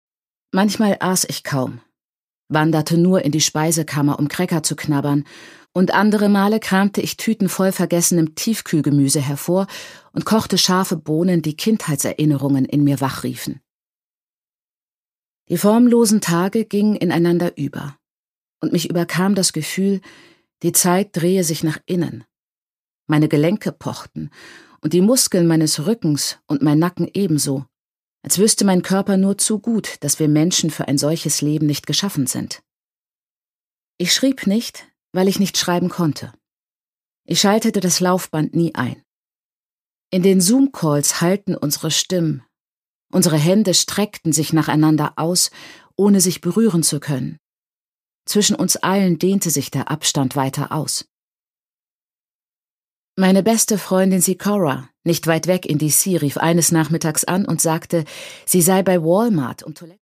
Chimamanda Ngozi Adichie: Dream Count (Ungekürzte Lesung)
Produkttyp: Hörbuch-Download